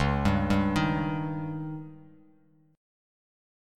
C#sus2b5 chord